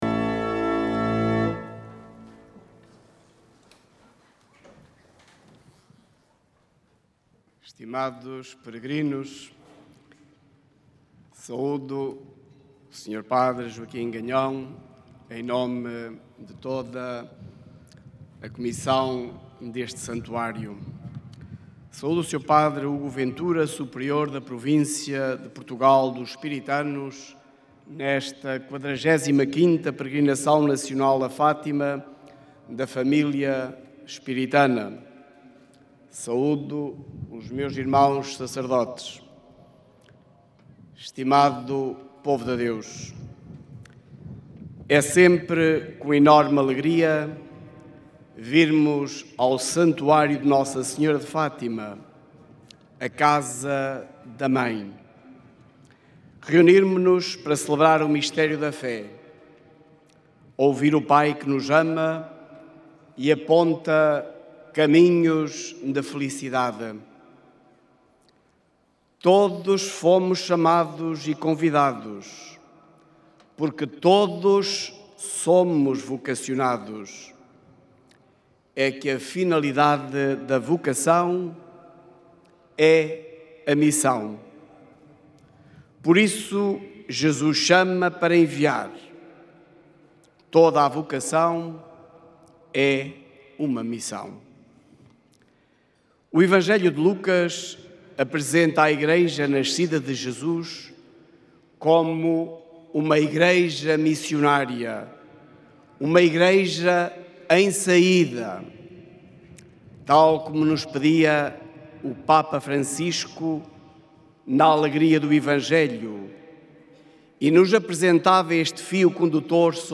Entre os peregrinos estiveram os espiritanos, reunidos no Recinto de Oração na Eucaristia dominical, atentos à homilia de D. Delfim Gomes na manhã deste 6 de julho. O espírito de missão, em sentido amplo e diverso, foi o epicentro da reflexão.